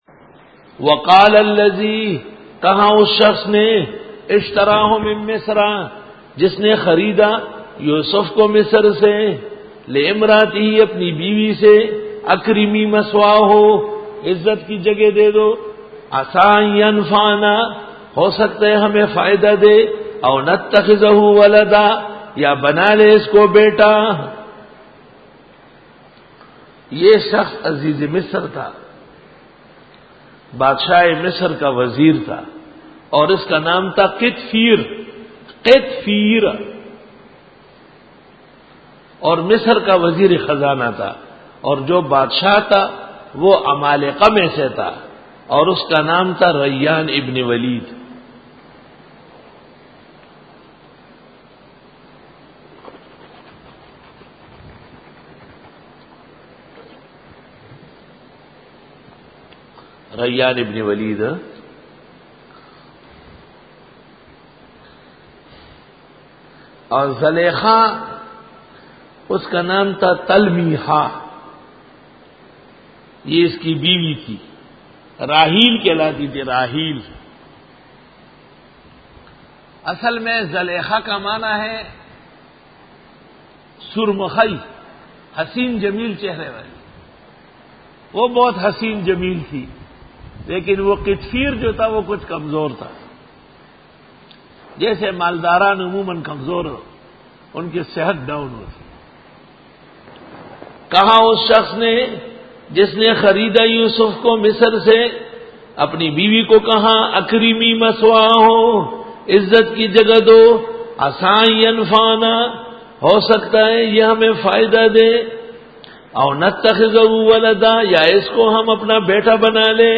Dora-e-Tafseer 2008